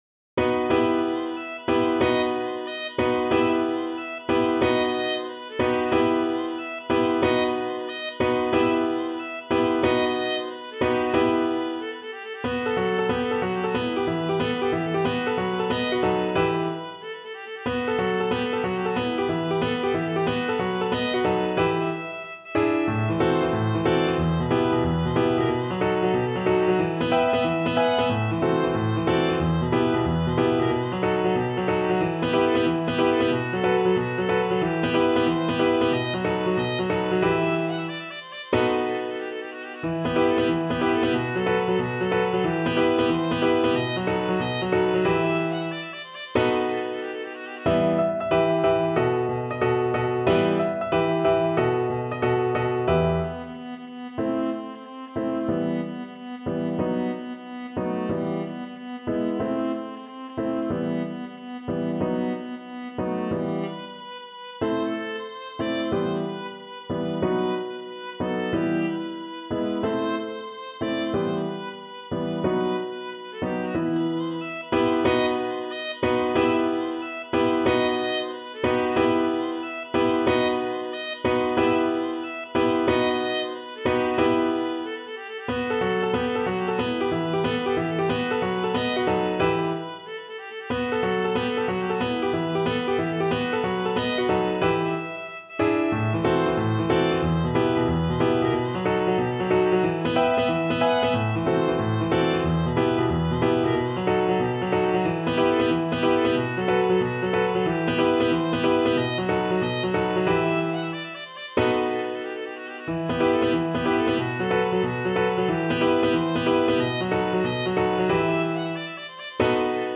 Viola
Pizzica music is known for its fast-paced rhythms, intricate melodies, and infectious energy.
E minor (Sounding Pitch) (View more E minor Music for Viola )
Molto allegro .=c.184
6/8 (View more 6/8 Music)